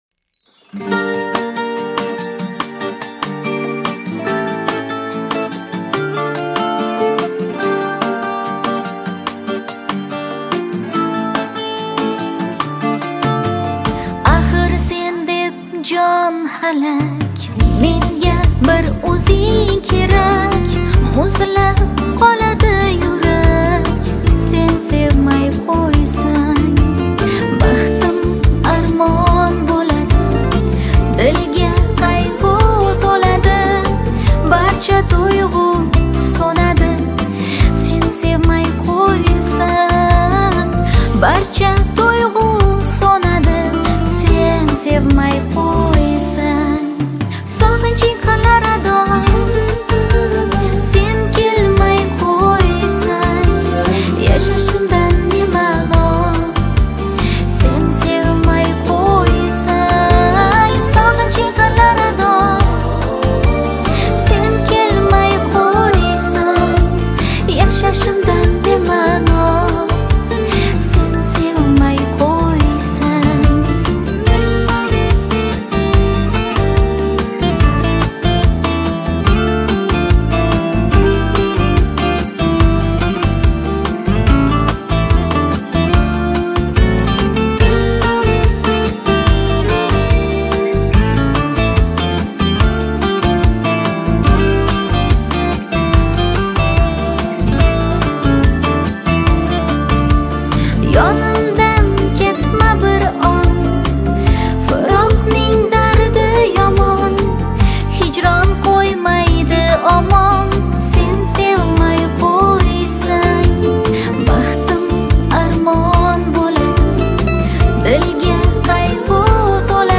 • Категория: Узбекские песни